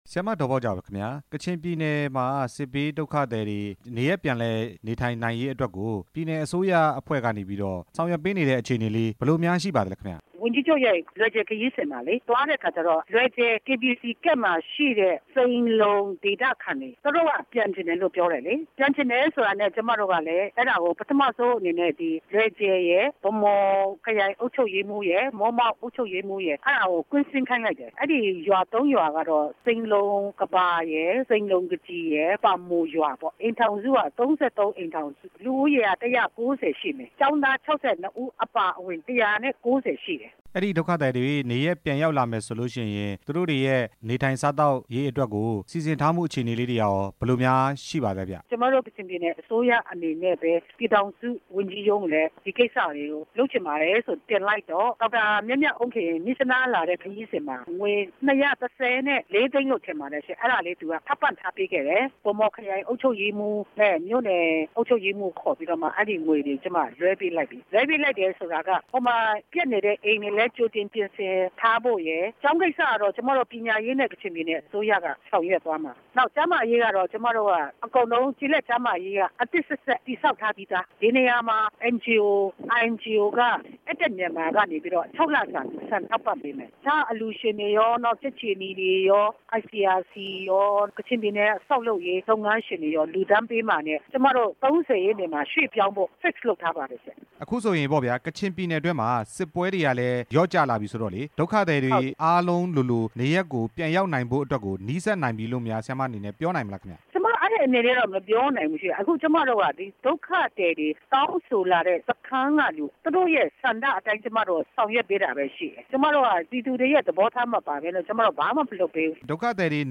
ကချင်ပြည်နယ် လူမှှုရေးရာဝန်ကြီး ဒေါ်ဘောက်ဂျာနဲ့ မေးမြန်းချက်